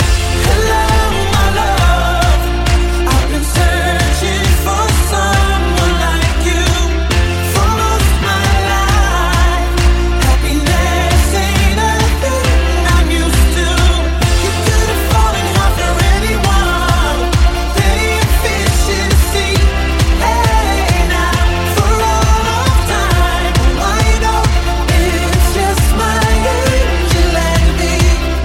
Genere: pop, latin pop, remix